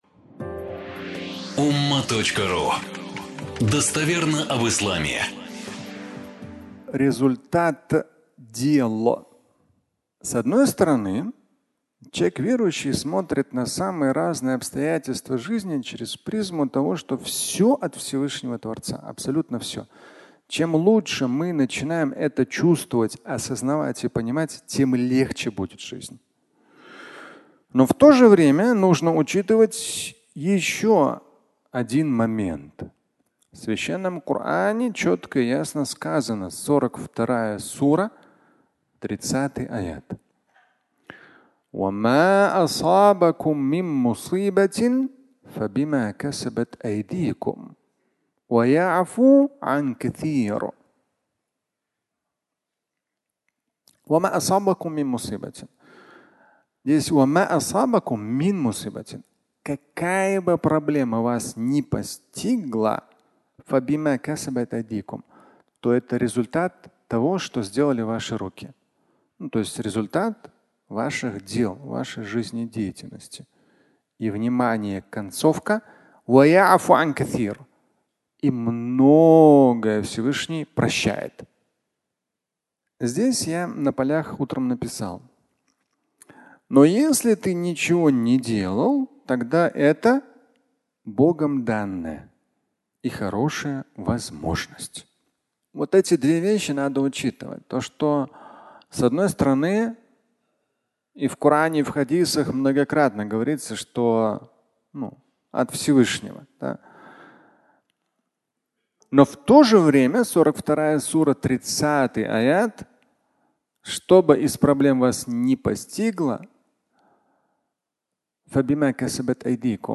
Результат дел (аудиолекция)